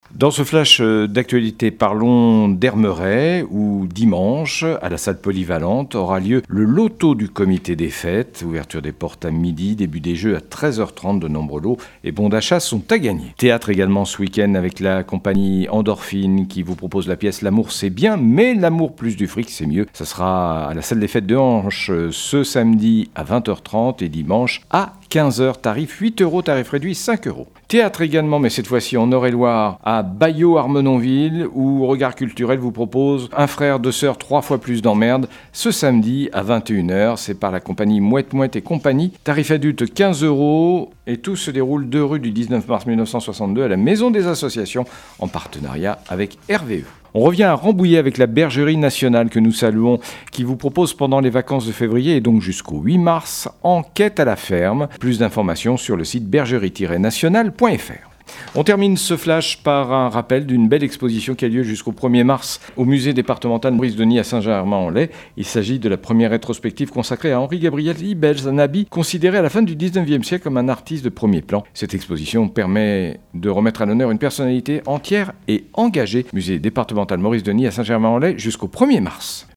L'information locale
20.02-flash-local-matin.mp3